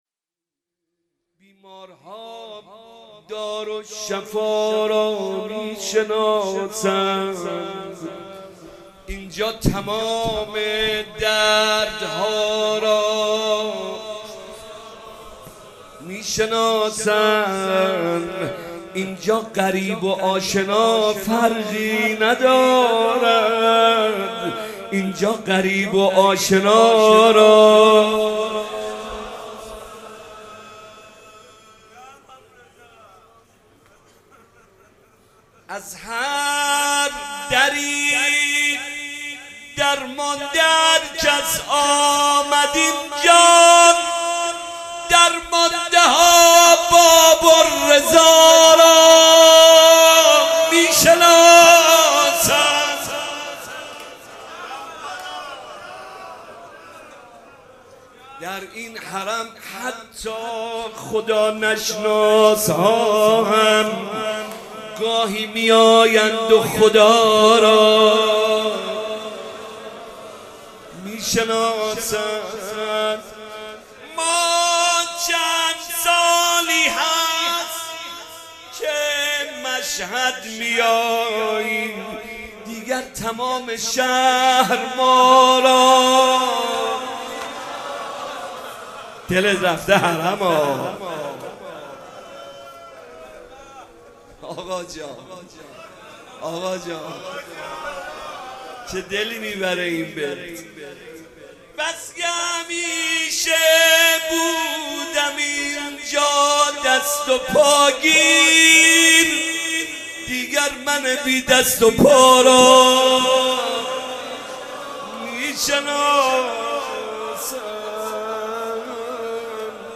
مداحان